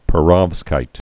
(pə-rŏvzkīt, -rŏfs-)